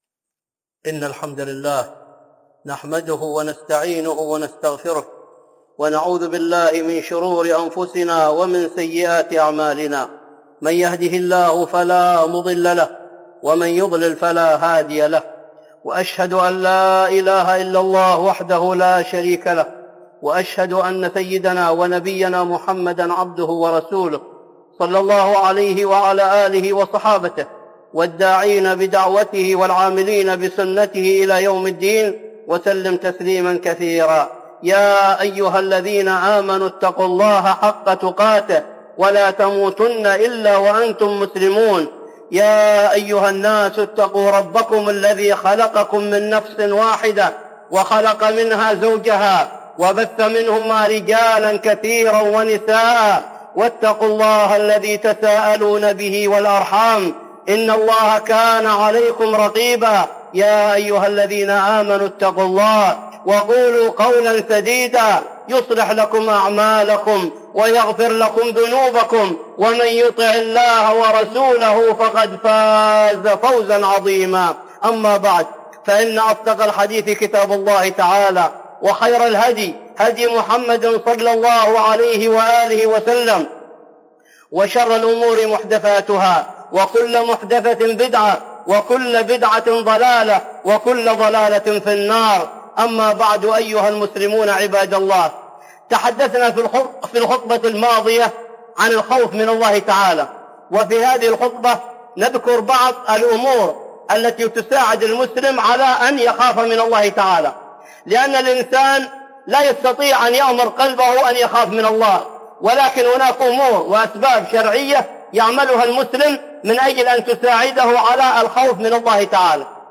(خطبة جمعة) أسباب الخوف من الله